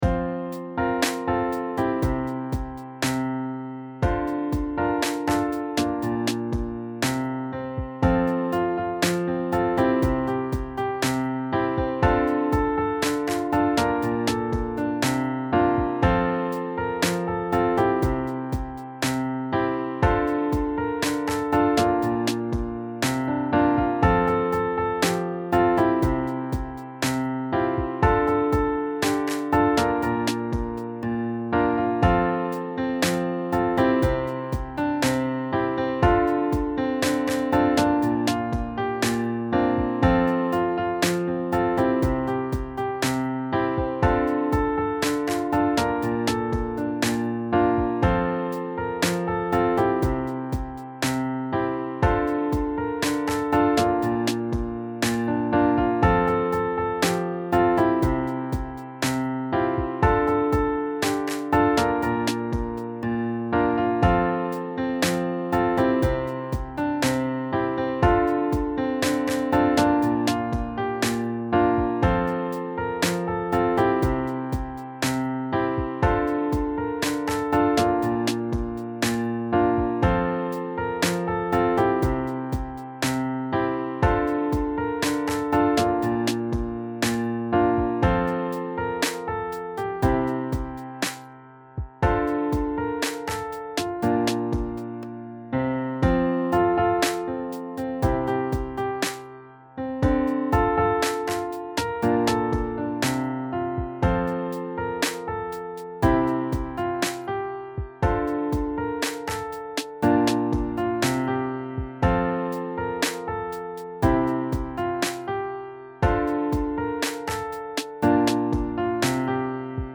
(just instruments) or the choir, please click on the